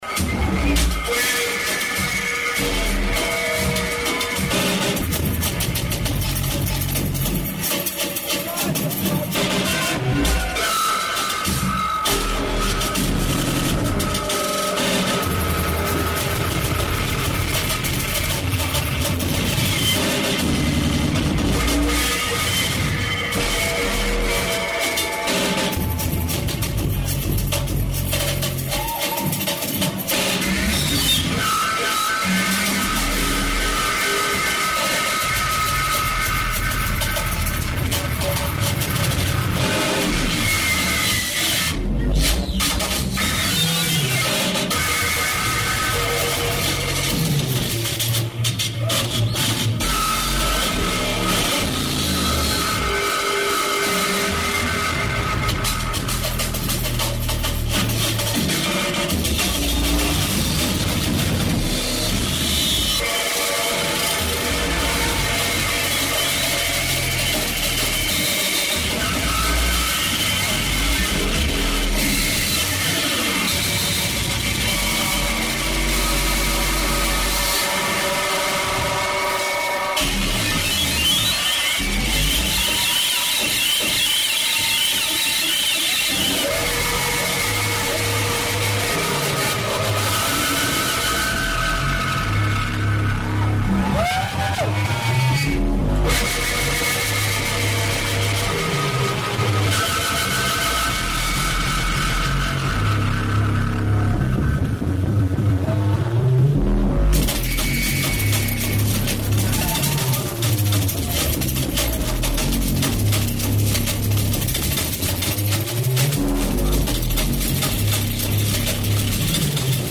venue Prince Bandroom